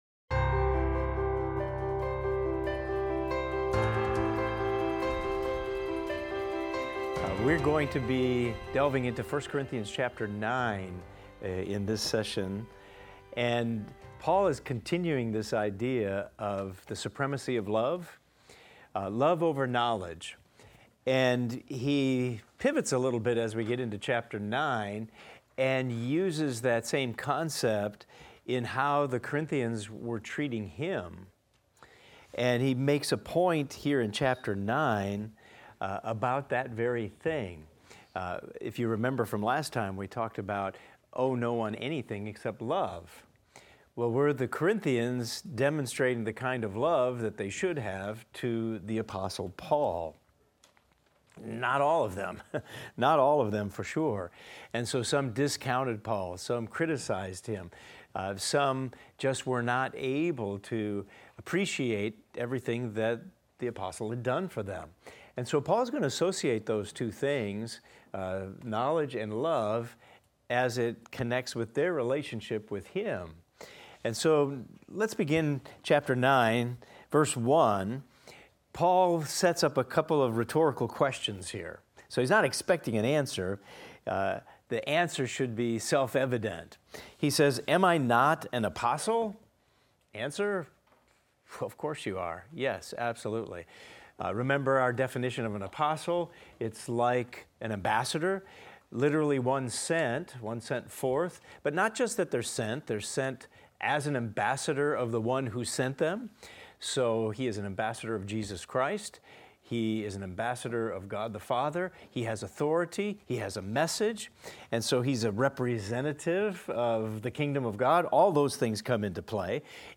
In this class we will discuss 1 Corinthians 9:1–24 and examine the following: Paul defends his apostleship, asserting his right to receive support but choosing to forgo it for the sake of the gospel.